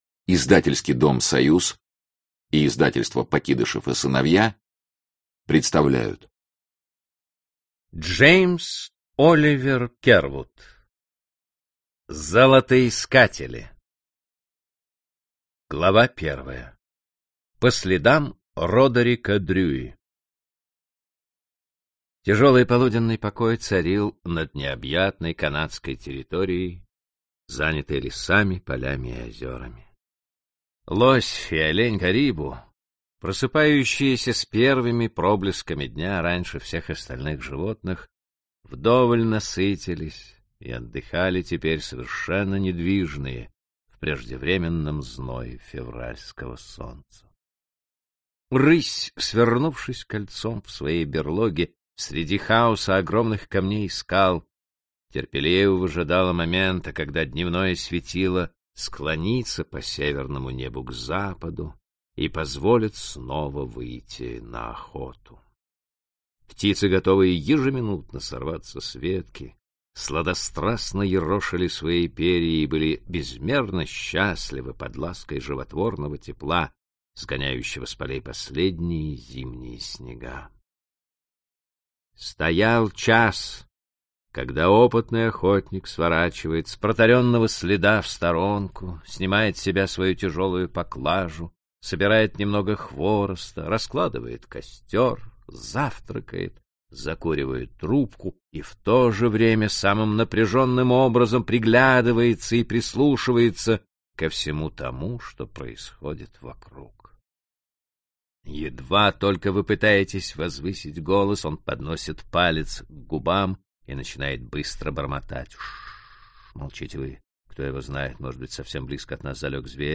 Аудиокнига Золотоискатели | Библиотека аудиокниг
Прослушать и бесплатно скачать фрагмент аудиокниги